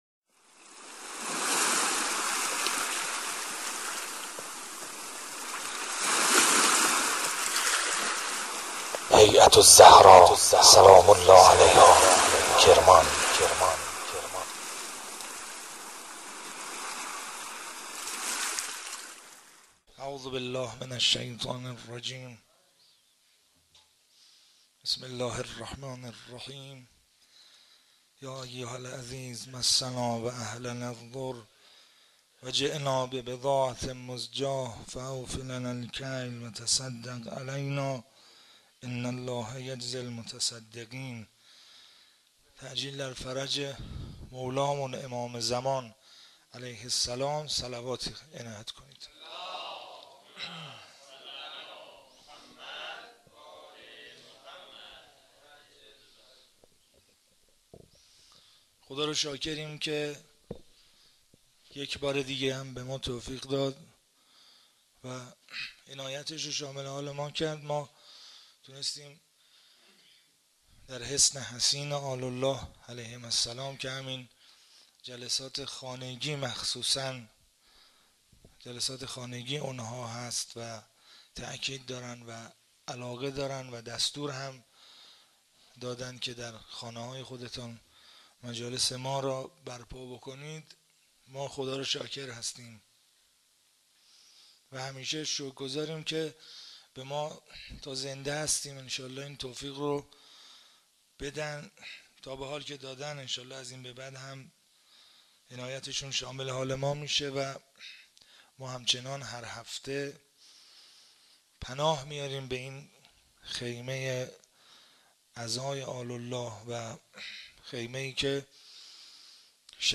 جلسه هفتگی20بهمن95
سخنرانی مذهبی